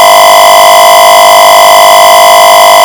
Bell
The bell sound effect is produced by the routine at 26450.
bell.ogg